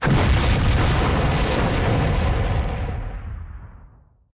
OtherDestroyed6.wav